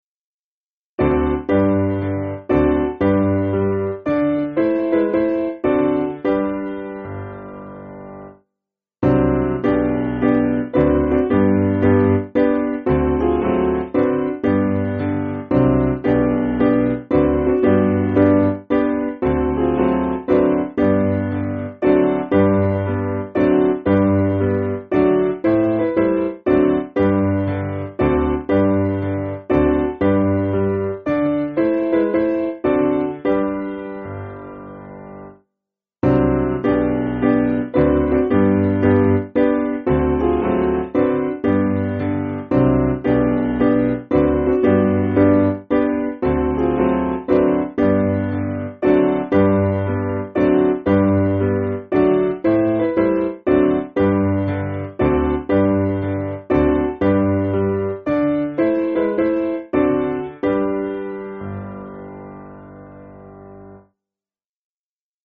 Simple Piano
(CM)   2/G